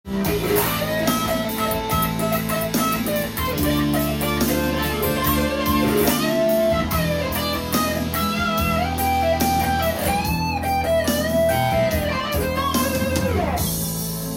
歪んだ音にワウペダルをかけて　当時の観客達を
ギターソロでもワウペダルは大活躍です！